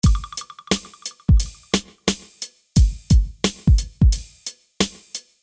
Tag: 88 bpm Hip Hop Loops Drum Loops 939.72 KB wav Key : Unknown